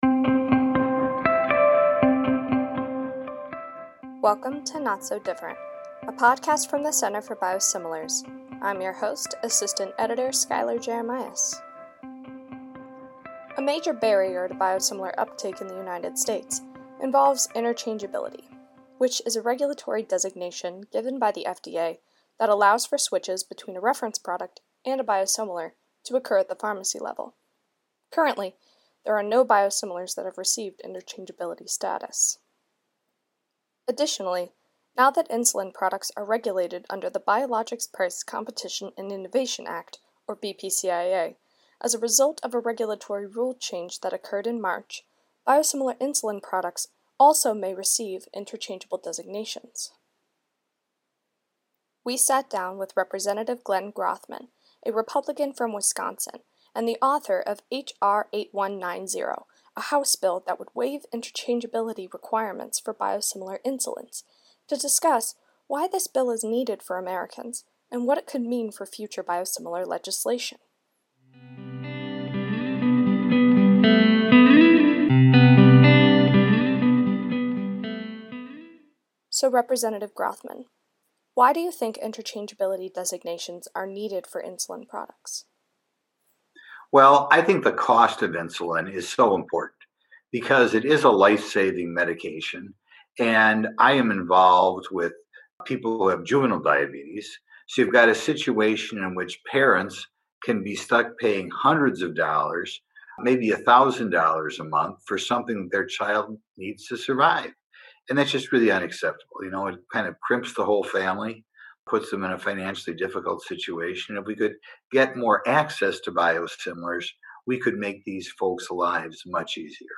We sat down with Representative Glenn Grothman [R-Wisconsin], the author of HR 8190, a bill that would waive interchangeability requirements for biosimilar insulins, to discuss why this bill is needed for Americans and what it could mean for future biosimilar legislation.